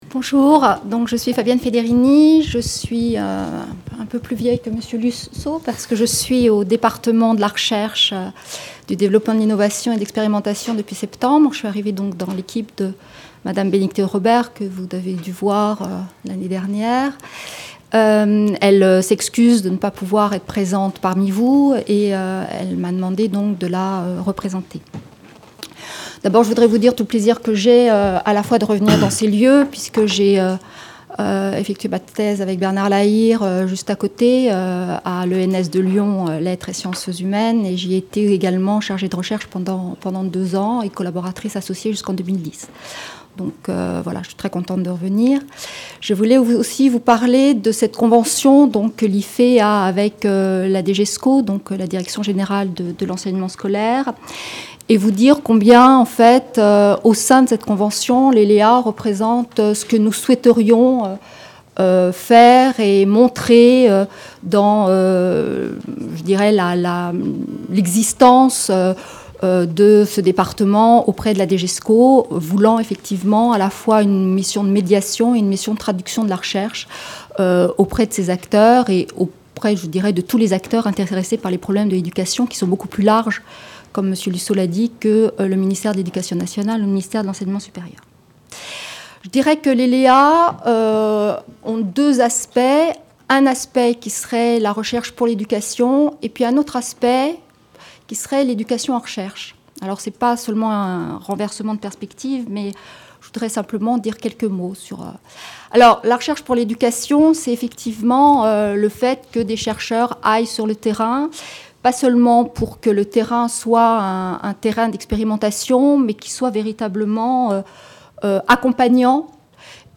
A propos des LéA: intervention
dans les cadre du séminaire sur les outils des LéA, en octobre 2012.